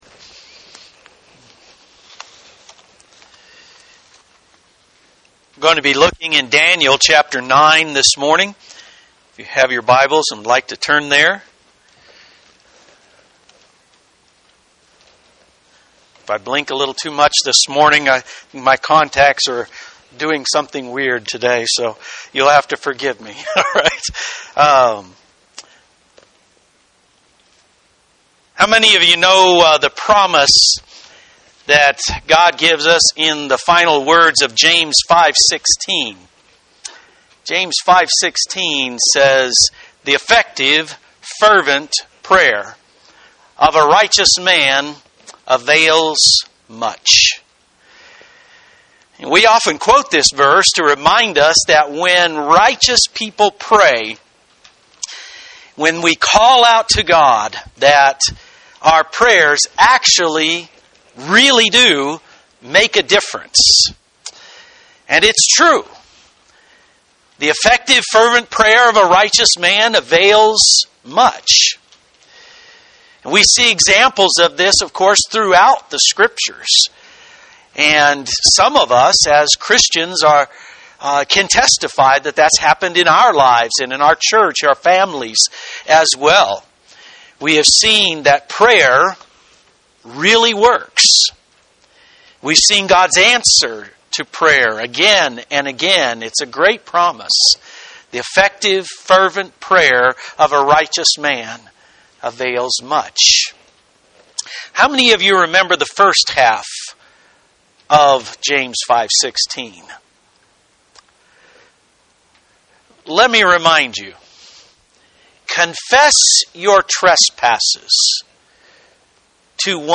The Prayer of Daniel (Part 1) Daniel 9 Read Complete Sermon Notes …